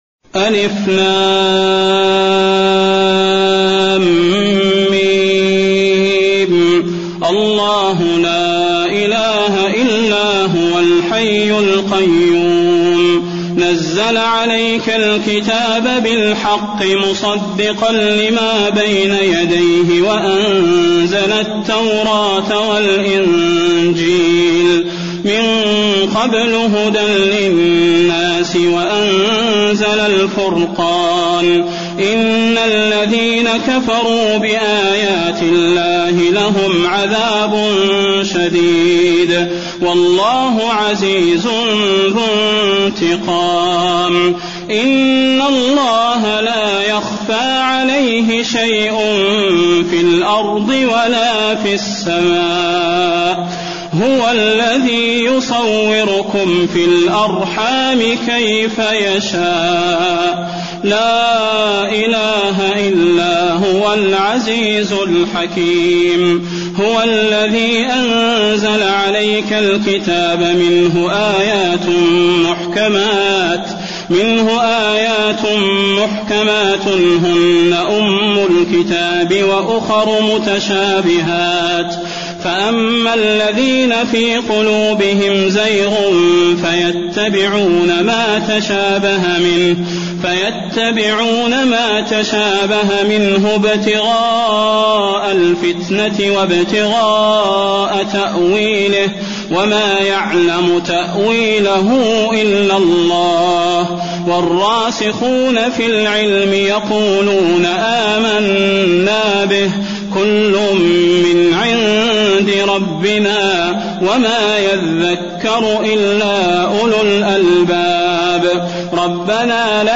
المكان: المسجد النبوي آل عمران